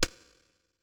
PixelPerfectionCE/assets/minecraft/sounds/item/shovel/flatten4.ogg at mc116
flatten4.ogg